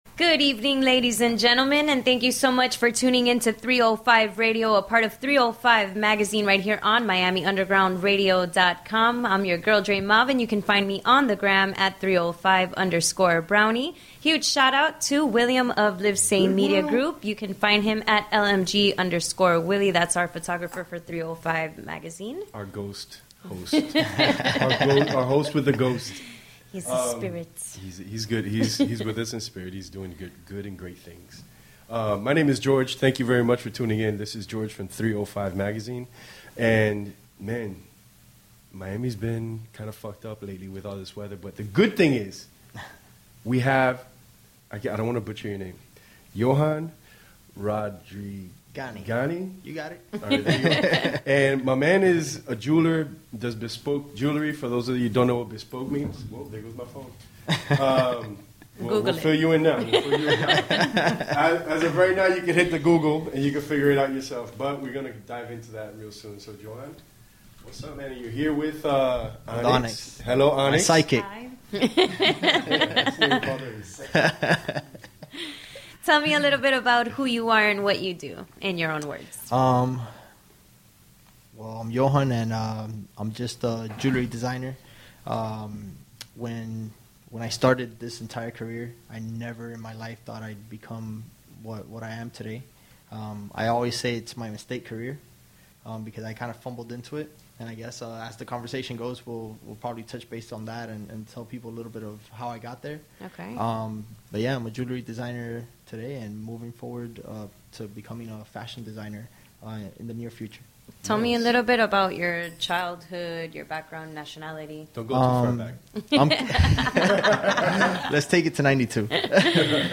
This is not like any of our other interviews.